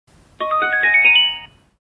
小奥系统启动提示音.MP3